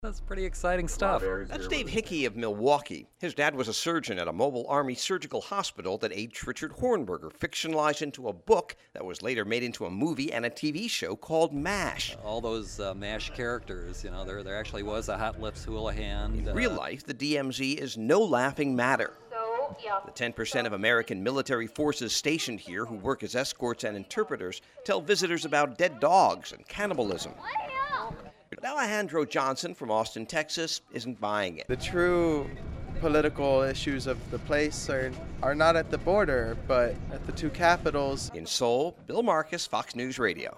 (DMZ, KOREAN BORDER) JUNE 27, 2010 – THE KOREAN WAR STARTED 60 YEARS AGO FRIDAY. IT ENDED WITH A CEASE FIRE LINE THIRTY-THREE MILES NORTH OF SEOUL.